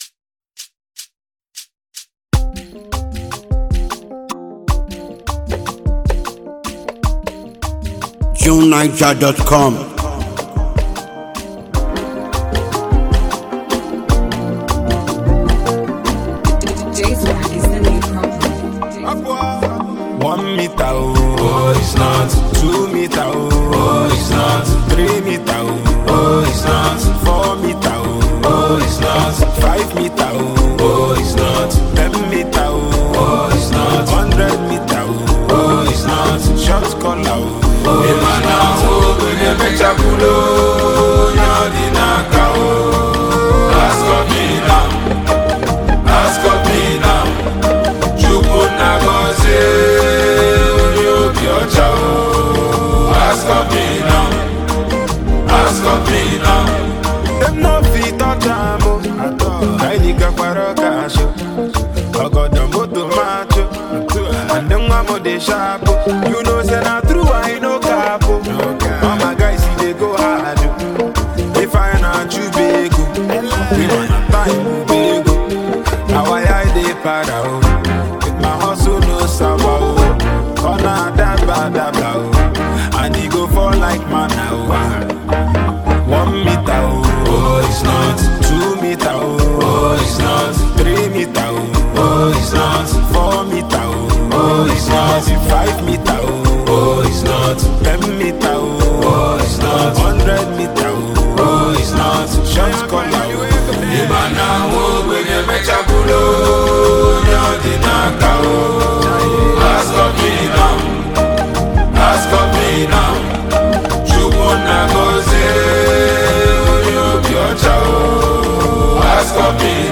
poignant
melodic rapping and flawless Igbo delivery